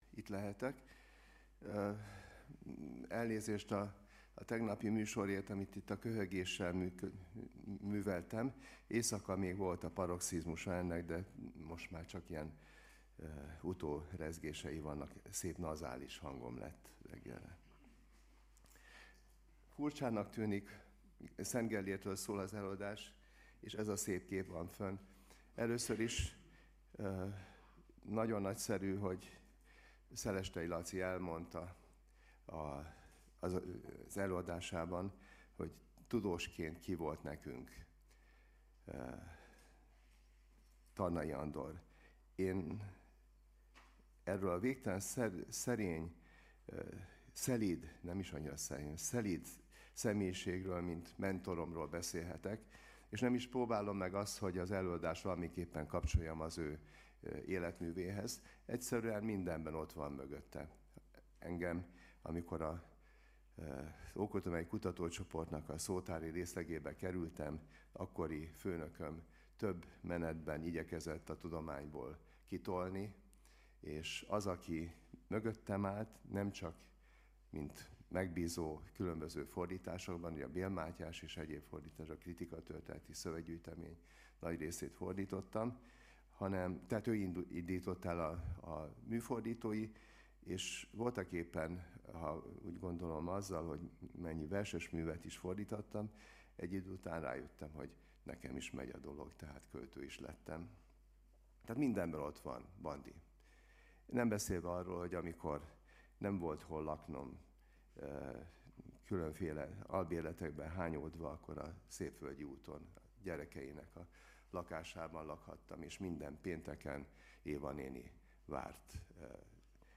Csatornák Hagyományőrzés és önbecsülés. Száz éve született Tarnai Andor , Negyedik ülés